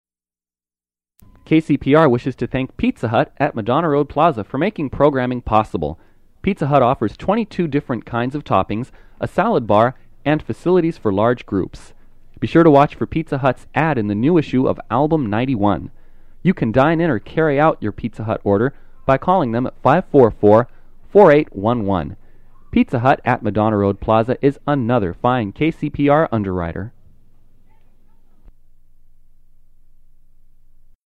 Underwriting announcement
Form of original Audiocassette